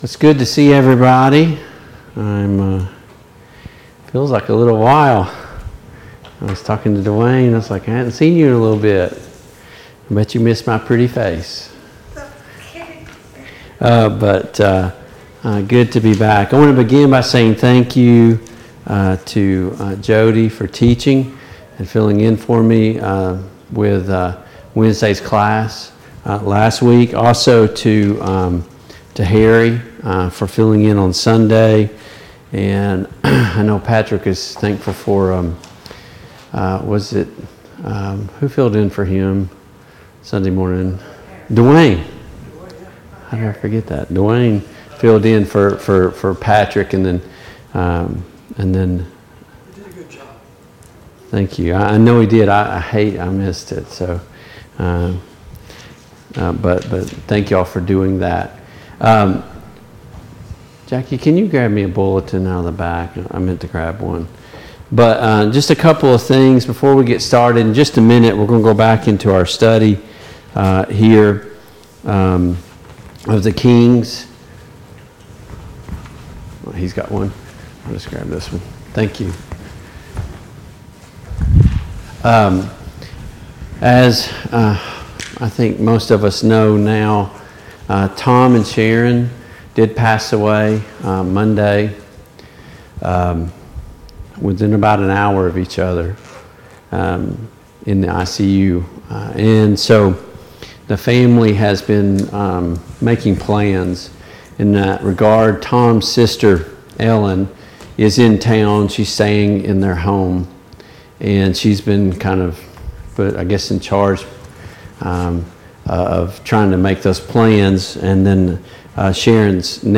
The Kings of Israel Passage: 2 Samuel 21, 2 Samuel 22, 2 Samuel 23, 2 Samuel 24 Service Type: Mid-Week Bible Study